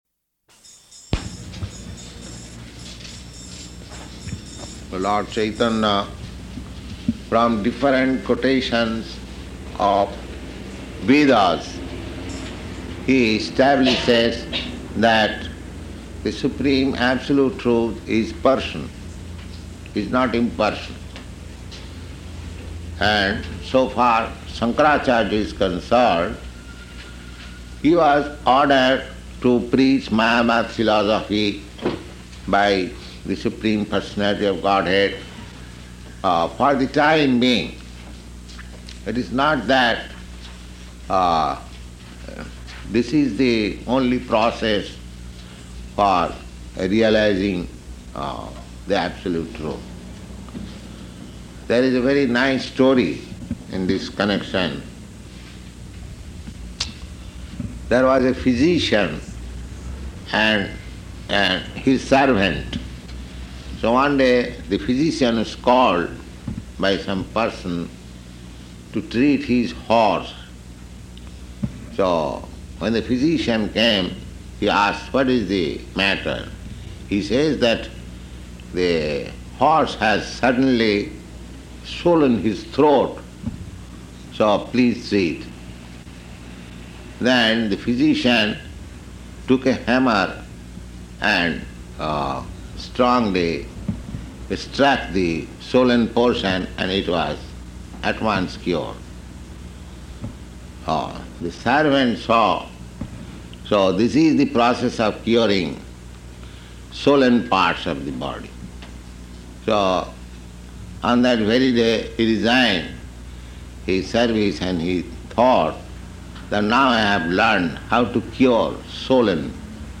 Location: San Francisco